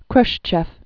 (krshchĕf, -chôf, r-shchyôf), Nikita Sergeyevich 1894-1971.